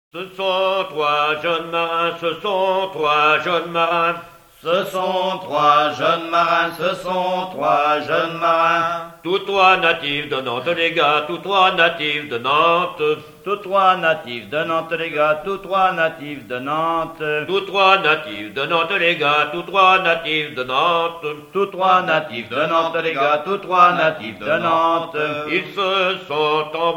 danse : ronde : rond de l'Île d'Yeu
Genre laisse
Enquête Mission Ile-d'Yeu
Pièce musicale inédite